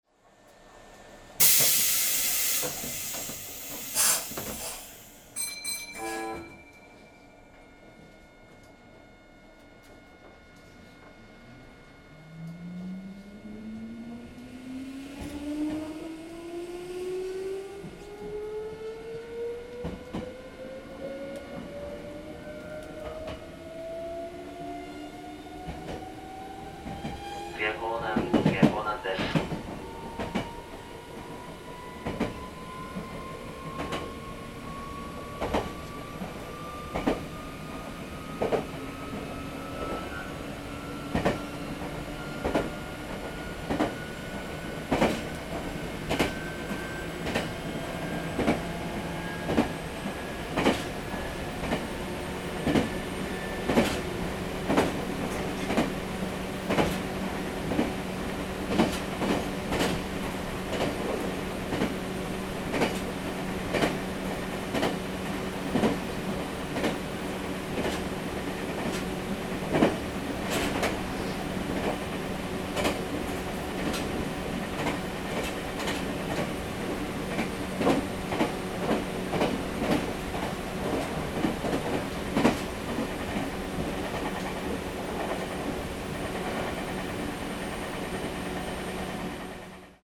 鉄道走行音ＣＤ 真栄館［既刊情報・京王電鉄7000系VVVF車 京王線 特急］
録音車両：モ７０１９
Ｄisc １　名鉄岐阜→名鉄名古屋 走行音
今回のCDは、その7000系を支線直通の優等運用である各務原線から河和線直通の急行で録音しました。
Meitetsu7000-2.mp3